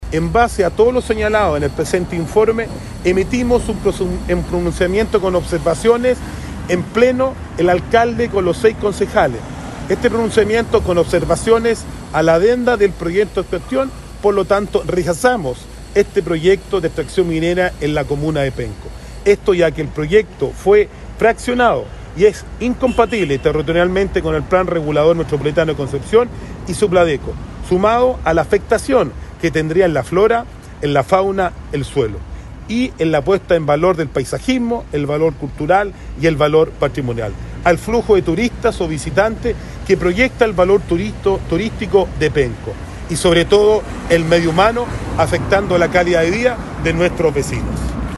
En tanto, el concejal Leonardo Jara aludió al rechazo ciudadano a la minería en Penco, a partir de la consulta ciudadana de 2022, y advirtió eventuales “irregularidades” en el avance de la iniciativa.